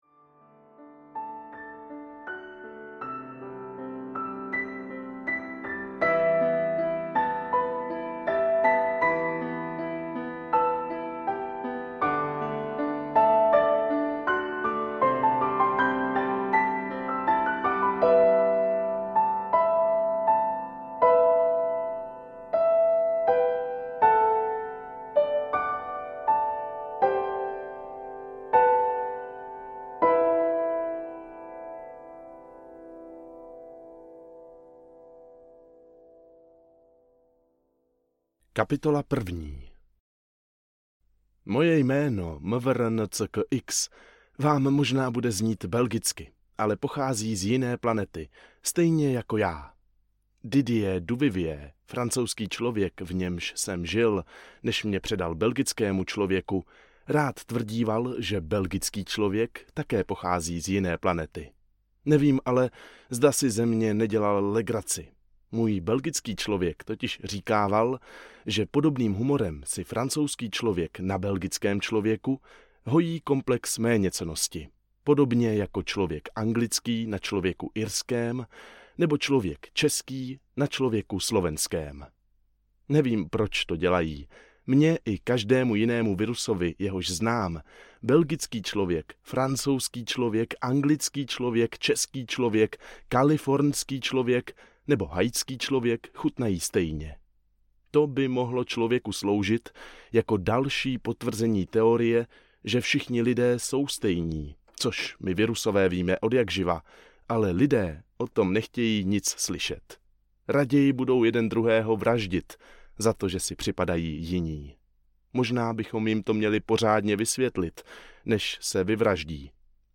Deník zamilovaného viruse audiokniha
Ukázka z knihy